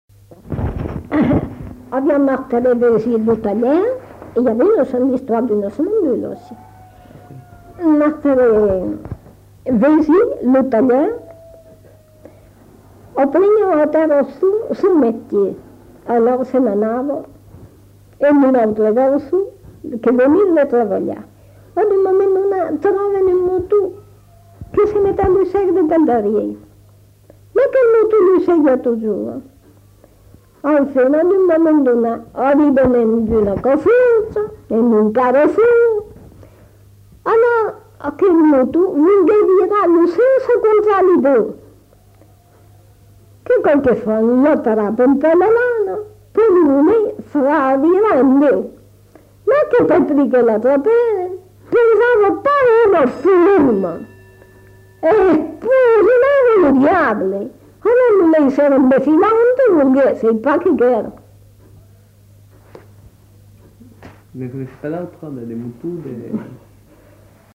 Aire culturelle : Périgord
Lieu : La Chapelle-Aubareil
Genre : conte-légende-récit
Effectif : 1
Type de voix : voix de femme
Production du son : parlé